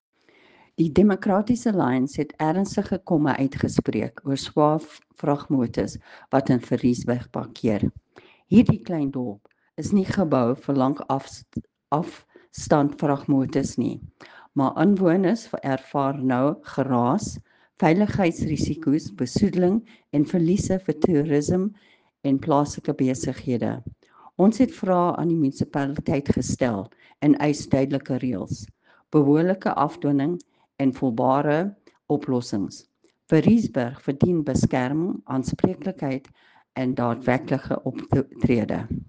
Afrikaans soundbites by Cllr Irene Rügheimer and